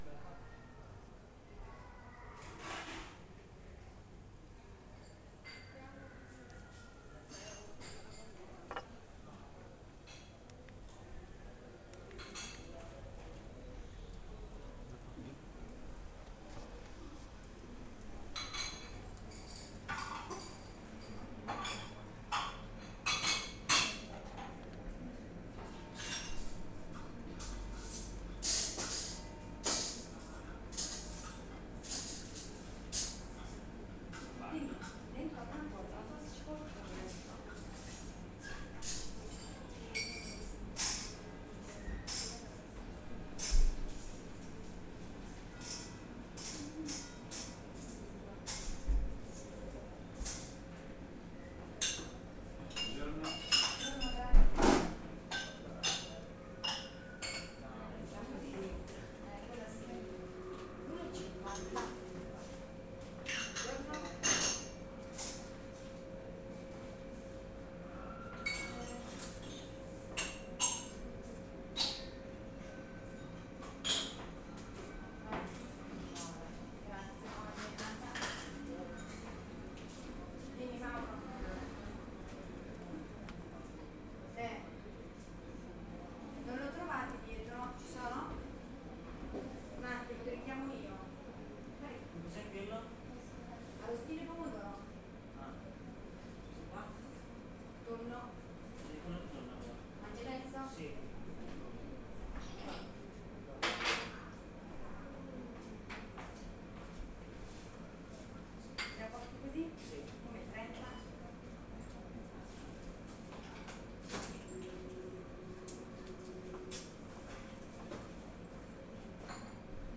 Coffee Bar in Italian Town Main Street_Caffé Del Corso_Soundscape_Fano
11 30 area bar bell bike breakfast cash sound effect free sound royalty free Sound Effects